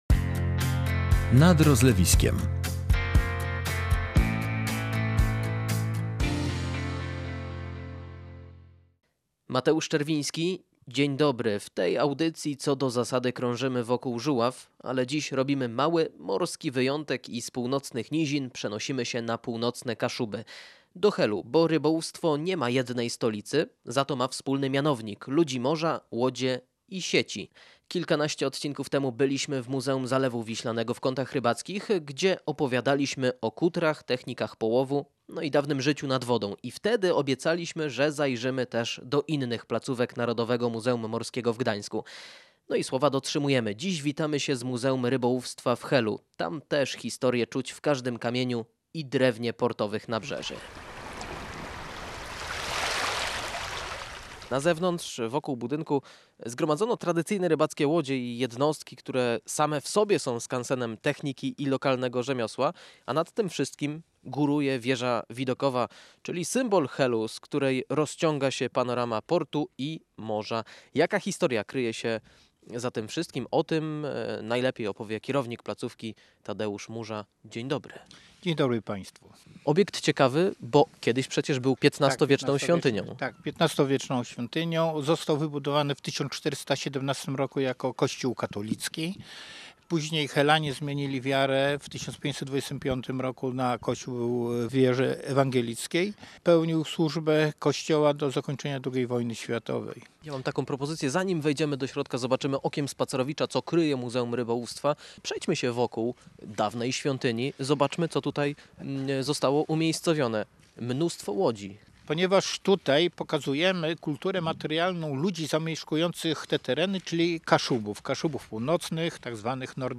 Witamy się z Muzeum Rybołówstwa w Helu.